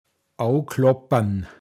pinzgauer mundart
abklappern, von einem zum anderen gehen åoklåppan